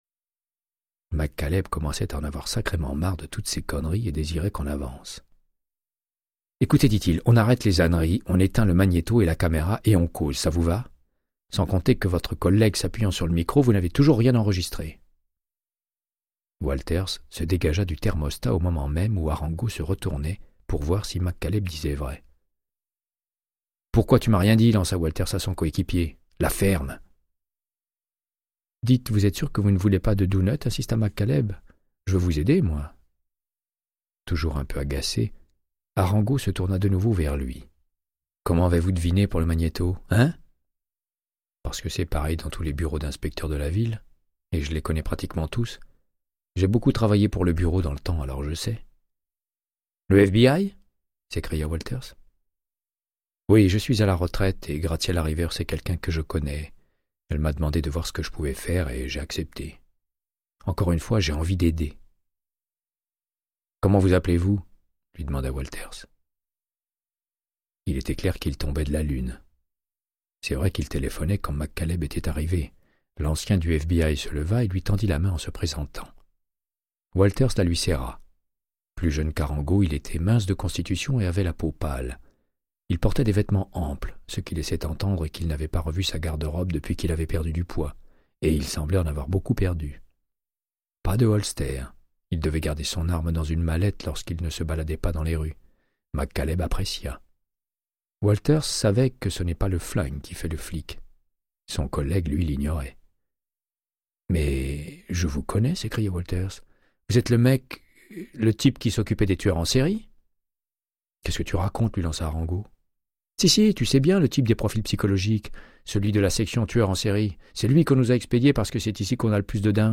Audiobook = Créance de sang, de Michael Connellly - 15